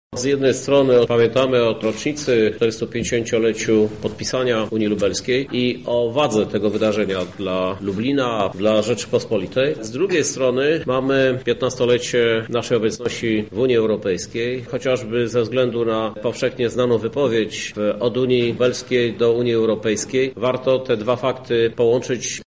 Więcej na temat wydarzenia mówi prezydent miasta Krzysztof Żuk: